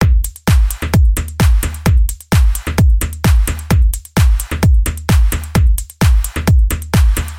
描述：浩室，技术，渐进式舞蹈
Tag: 130 bpm House Loops Drum Loops 1.24 MB wav Key : Unknown